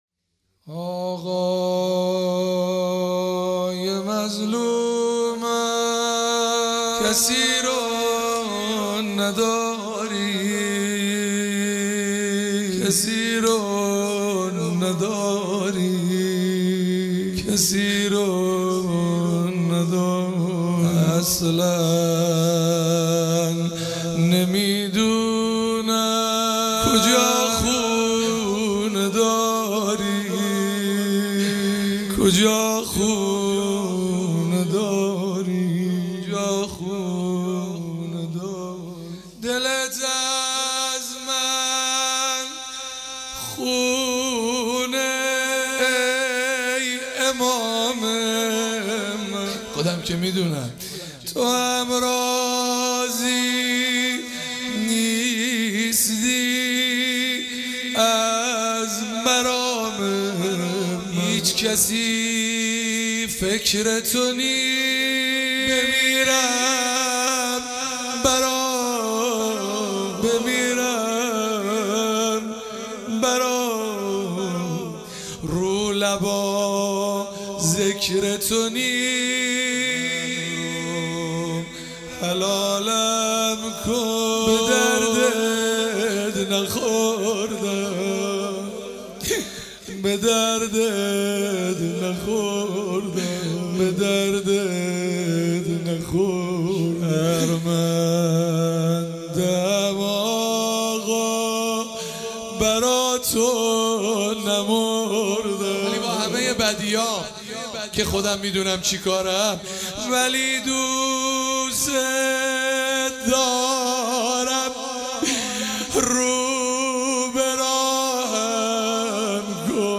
هیئت ام ابیها قم